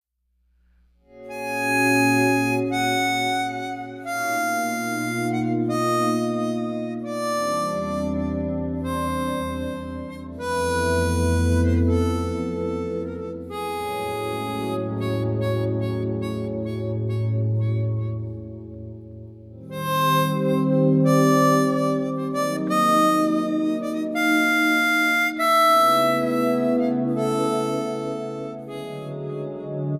Guitar
Harmonica